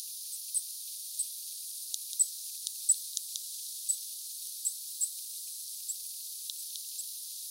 Onko se jokin puukiipijälintu?